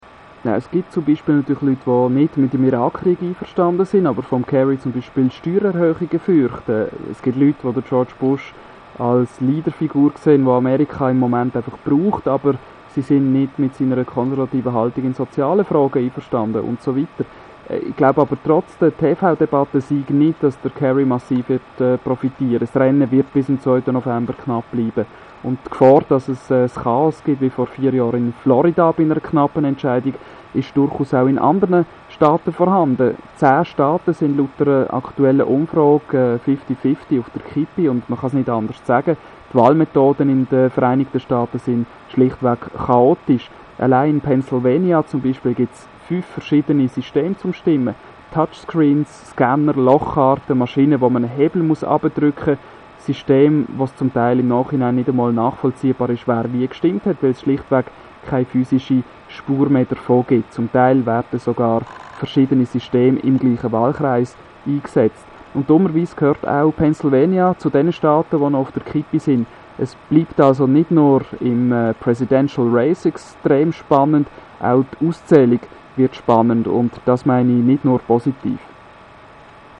Und dann also die Final Presidential Debate - verfolgt in Bennington, Vermont. meine Eindrücke gleich im O-Ton, so wie ich sie Radio ExtraBern geschildert habe:
Diese Quotes wurden natürlich auseinandergeschnipselt, das wäre alles viiiel zu viel.